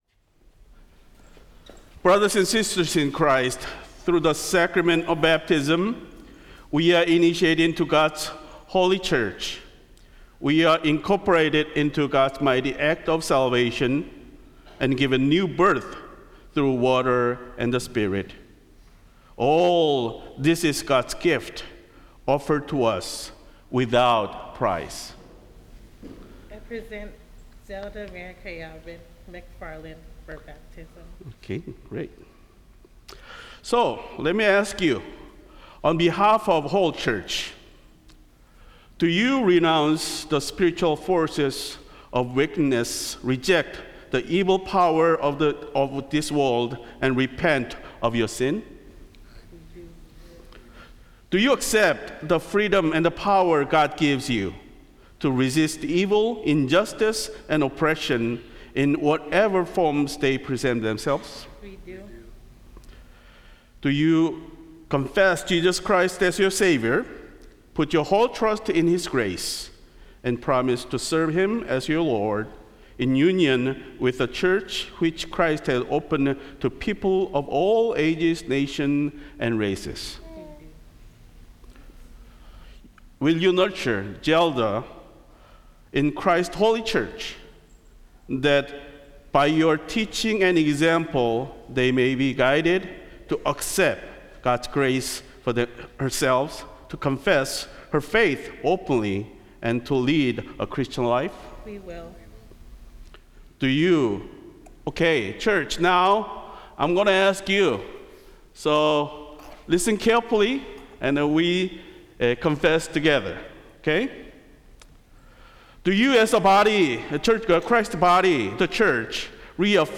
Service of Worship